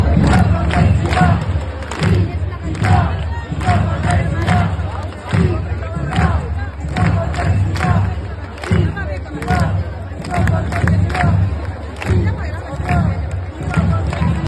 Gaderne var fyldt med gule og røde faner, solskin, sange, slagord og et stærkt kollektivt nærvær.